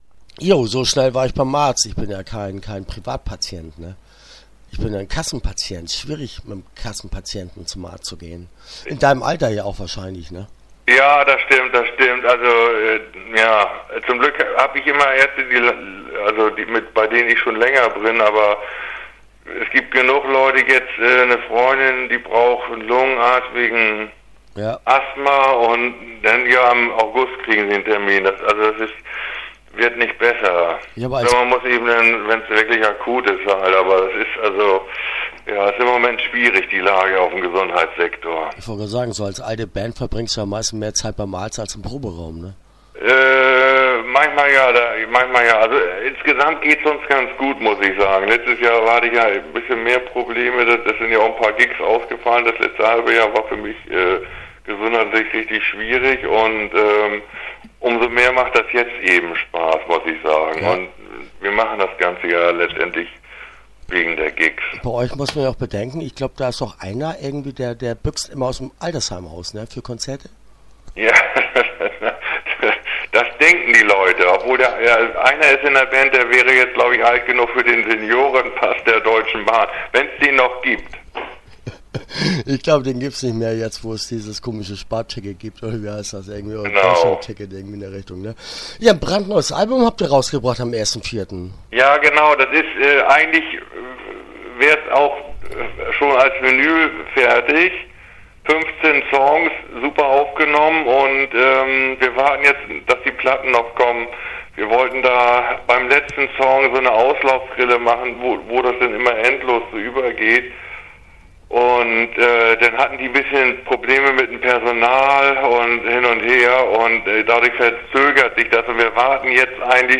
Blanker Hohn - Interview Teil 1 (12:15)